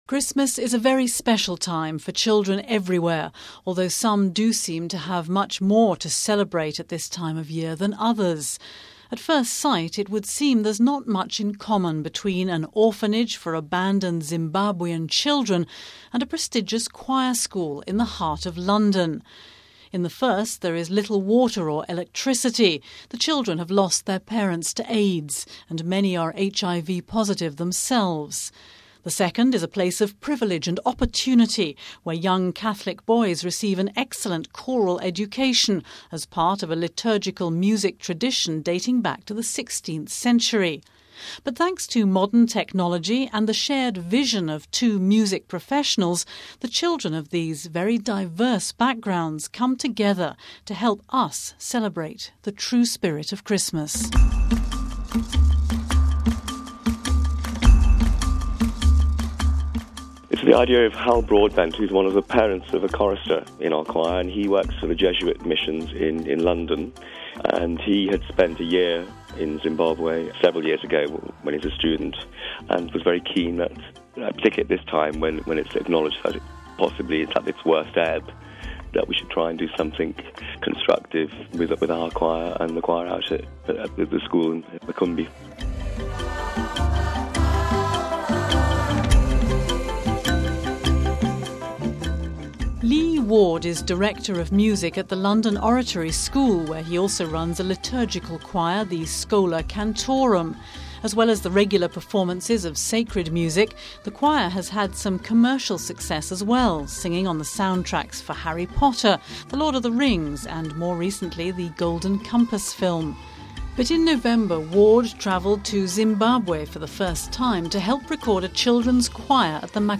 Home Archivio 2007-12-17 16:04:34 A DIFFERENT KIND OF CAROL Children from an orphanage in Zimbabwe join choirboys from the London Oratory School in a unique recording of this traditional Christmas carol....